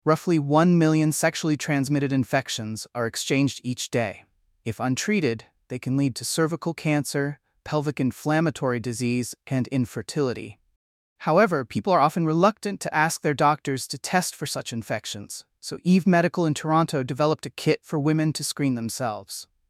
Sample answer: